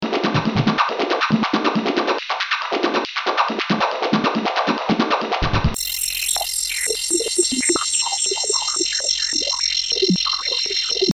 Various drum patterns